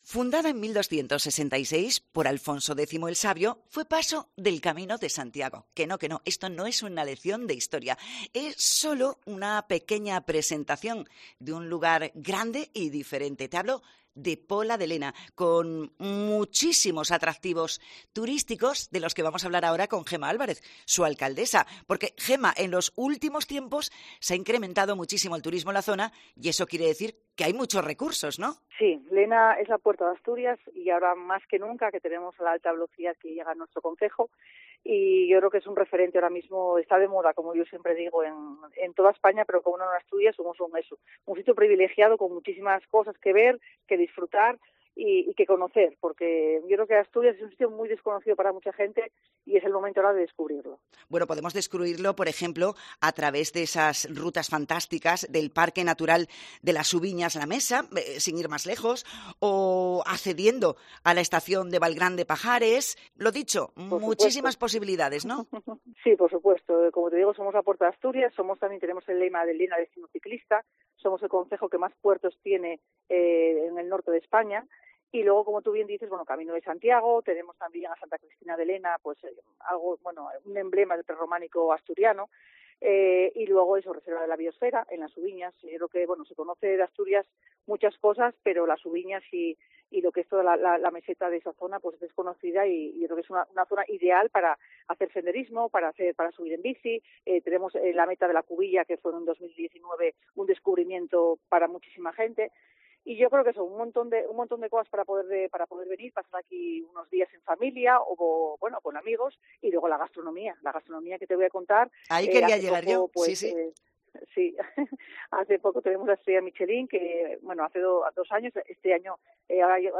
En el especial de COPE Asturias desde FITUR 2024, hablamos con la alcaldesa del concejo, Gema Álvarez: "Con la llegada del AVE, estamos más de moda que nunca en España"
FITUR 2024: Entrevista a Gema Álvarez, alcaldesa de Lena
Lo ha dicho en el programa especial que COPE Asturias emite desde Ifema.